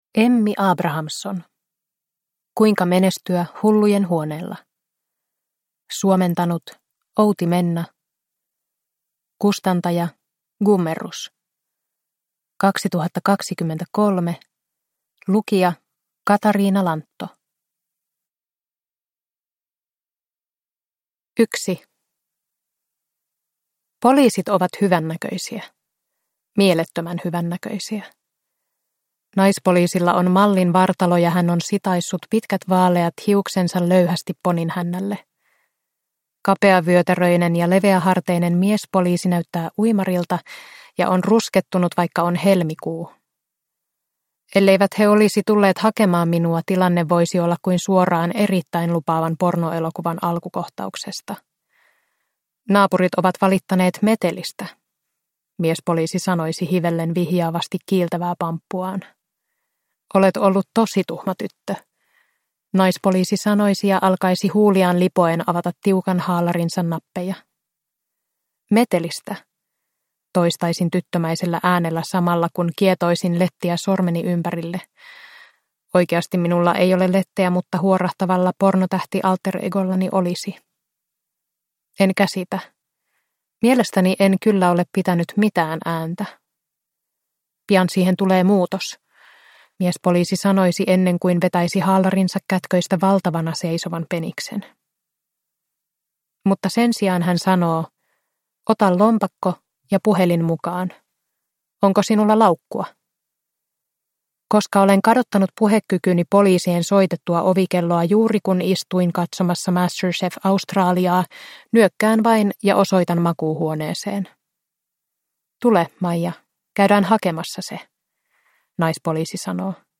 Kuinka menestyä hullujenhuoneella – Ljudbok – Laddas ner